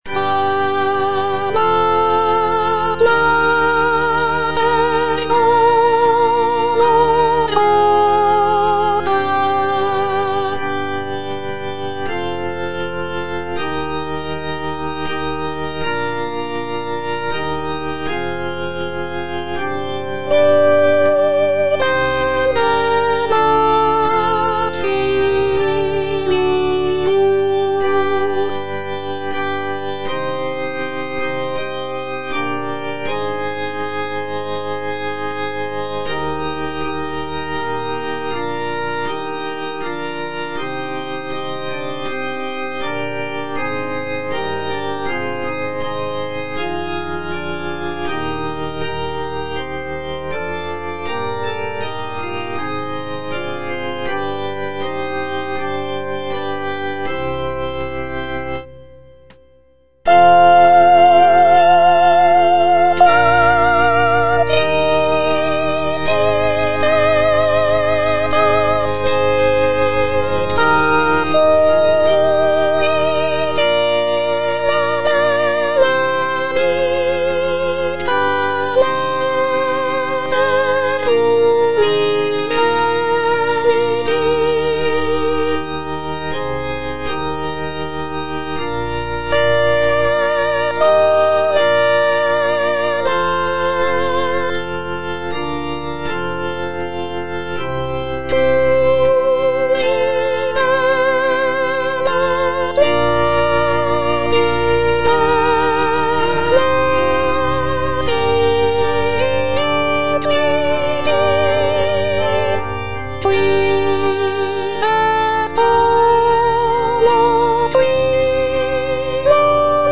G.P. DA PALESTRINA - STABAT MATER Stabat Mater dolorosa (soprano I) (Voice with metronome) Ads stop: auto-stop
Composed in the late 16th century, Palestrina's setting of the Stabat Mater is known for its emotional depth, intricate polyphonic textures, and expressive harmonies.